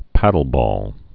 (pădl-bôl)